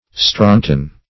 strontian - definition of strontian - synonyms, pronunciation, spelling from Free Dictionary
strontian - definition of strontian - synonyms, pronunciation, spelling from Free Dictionary Search Result for " strontian" : The Collaborative International Dictionary of English v.0.48: Strontian \Stron"ti*an\, n. (Min.)